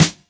Rich Top End Snare E Key 39.wav
Royality free snare drum sample tuned to the E note. Loudest frequency: 2517Hz
rich-top-end-snare-e-key-39-nTi.ogg